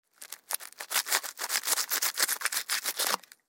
SFX锯切肉(Sawing Flesh)音效下载
SFX音效